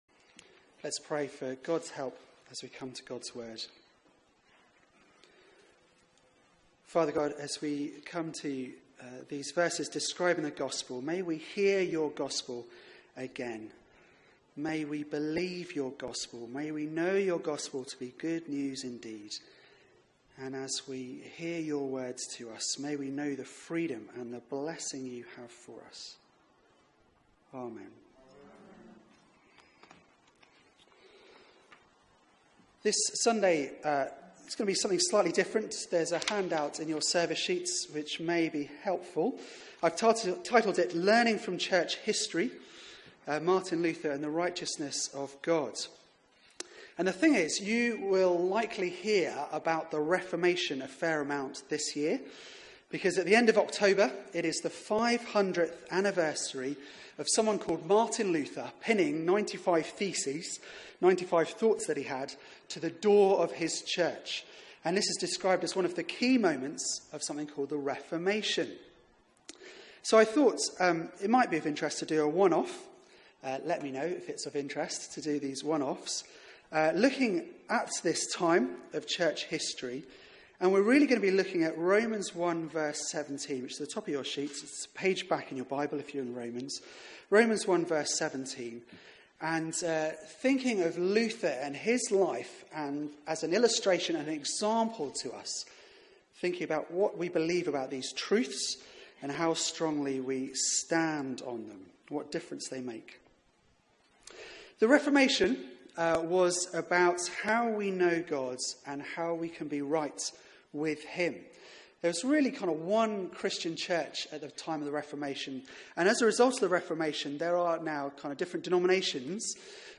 Media for 4pm Service on Sun 21st May 2017 16:00
Theme: Righteousness of God Sermon